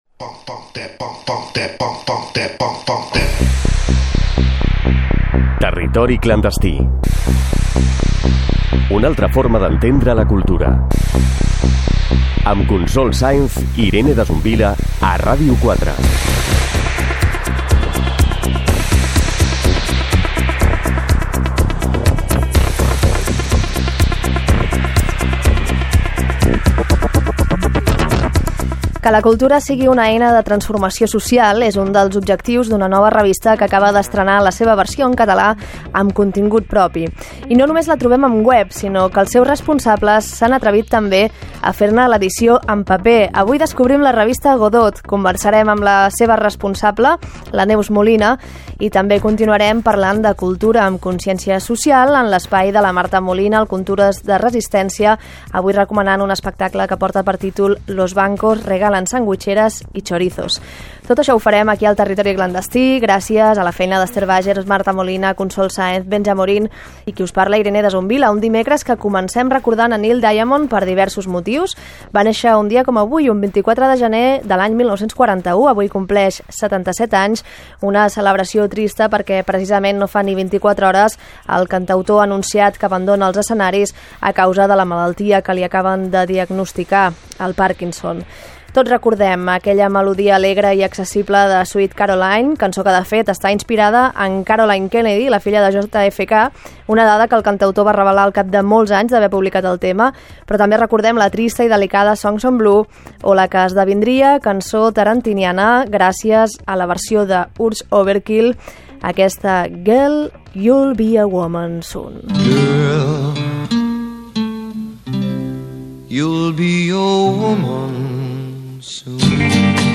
Careta del programa, sumari de continguts, equip del programa, dades sobre Neil Diamond que deixa els escenaris i tema musical